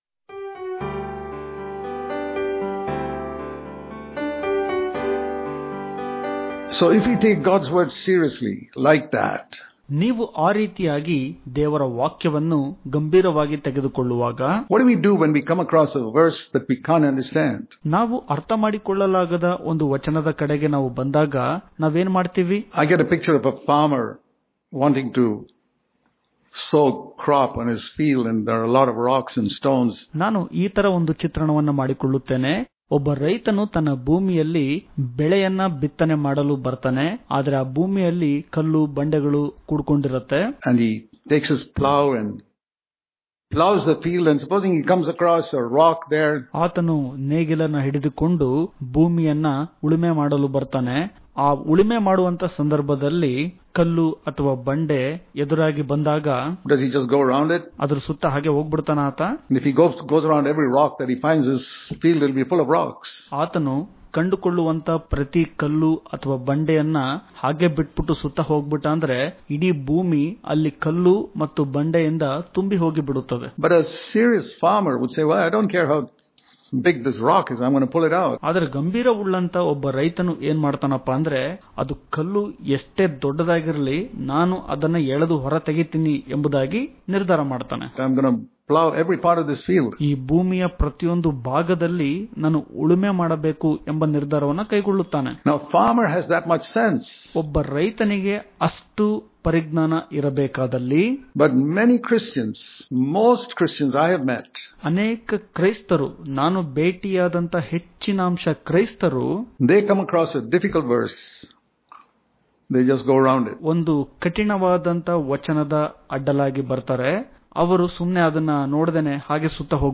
October 17 | Kannada Daily Devotion | Seek To Understand Every Word Of God Daily Devotions